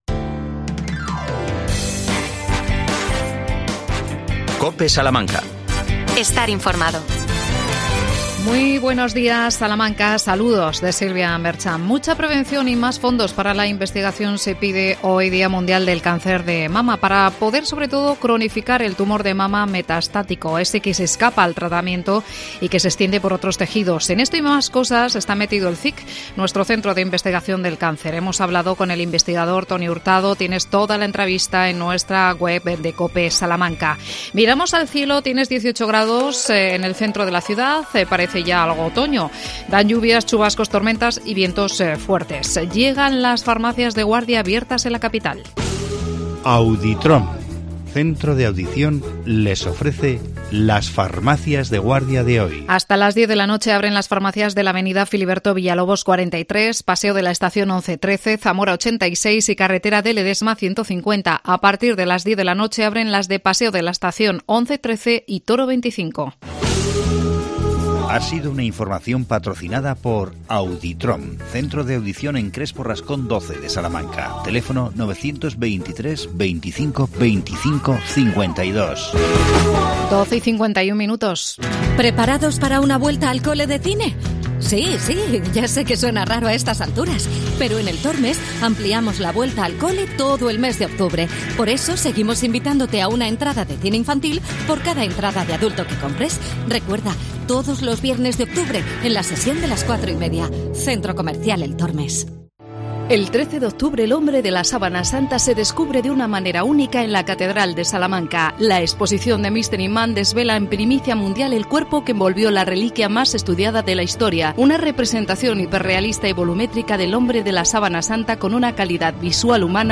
AUDIO: Entrevista a la concejala de Mayores Isabel Macías. Mañana arranca el I Congreso de Envejecimiento y Salud.